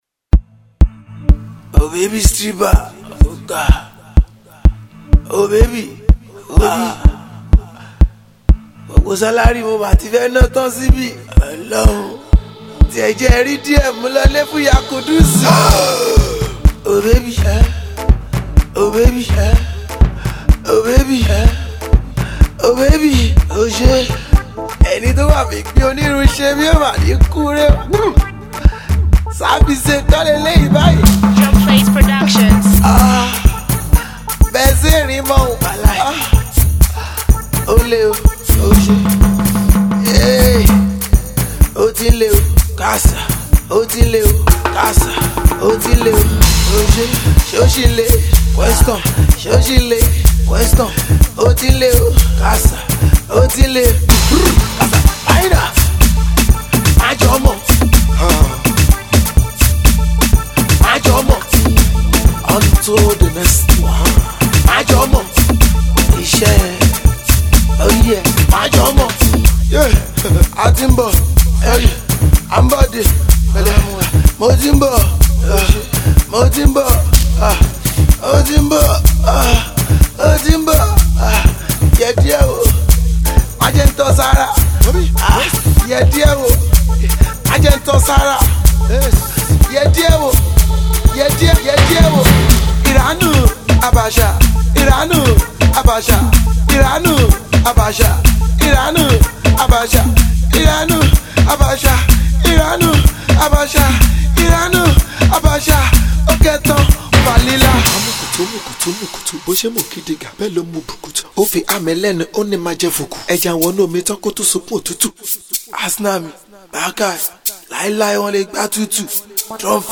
Alternative Indigenous Street Pop song